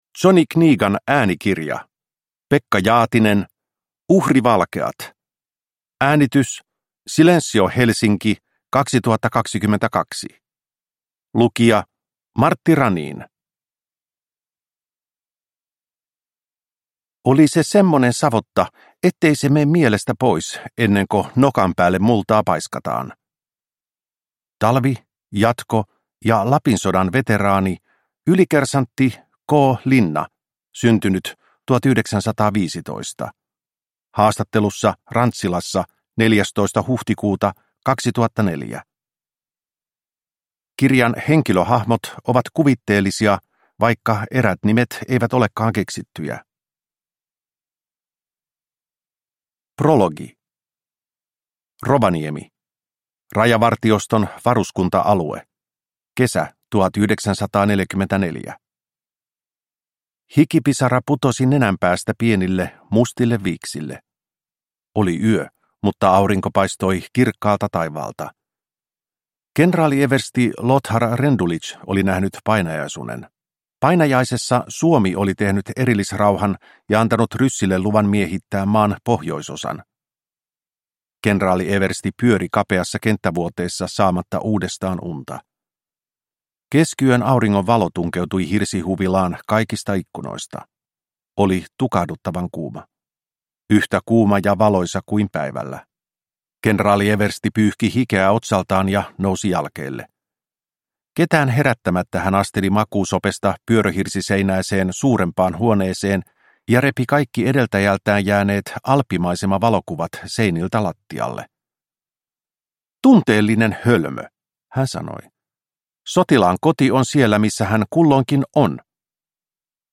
Uhrivalkeat – Ljudbok